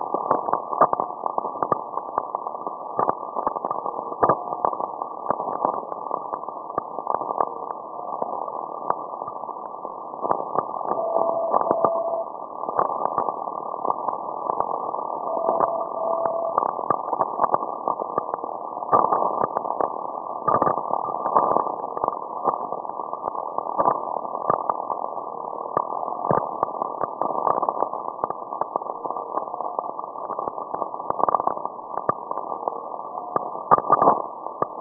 [Lowfer] Alpha on a degaussing coil